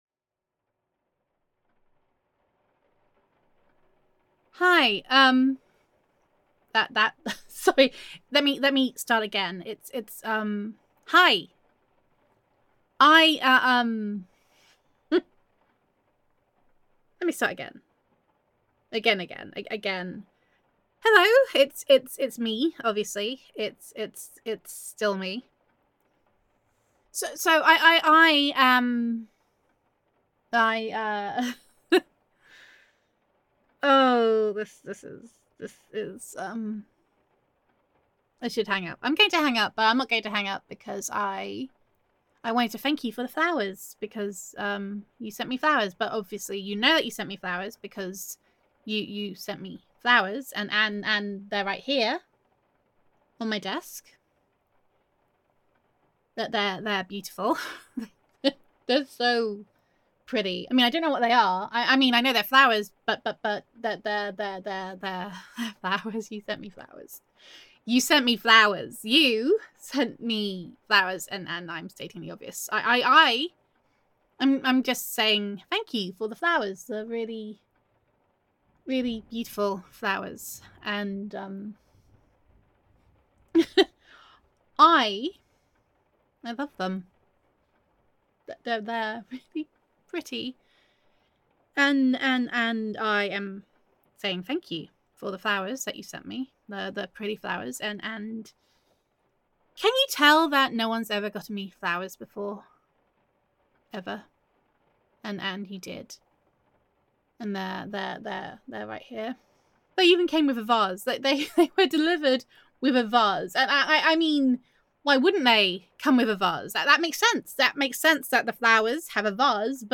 [F4A] Coming Up Roses [Girlfriend Roleplay][Girlfriend Voicemail][Voicemail][Flustered][Gender Neutral][You Bought Your Girlfriend Flowers and She Is Utterly Made Up]